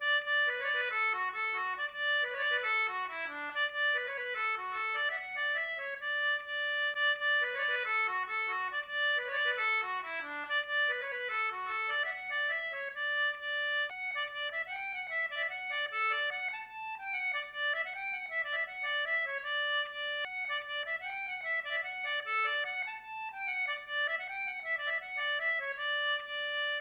Celtic Button Box Playlist Samples
POLKA
English concertina